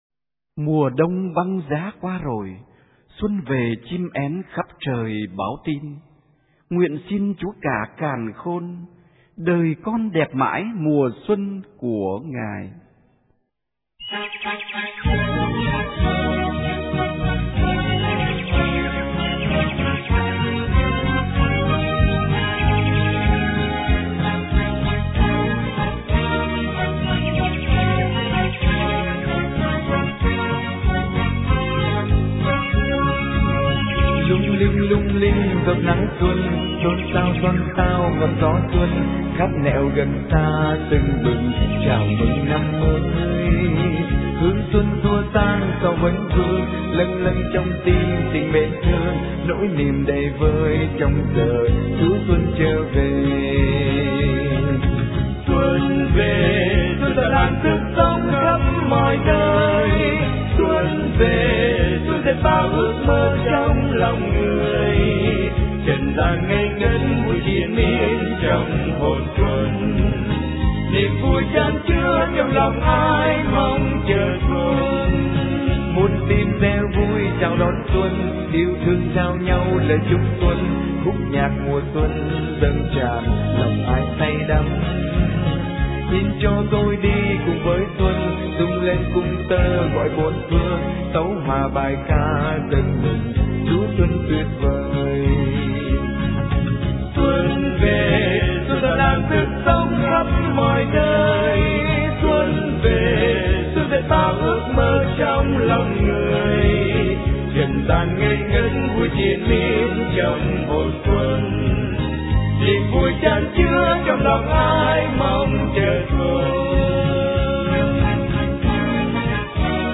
Dòng nhạc : Mừng Xuân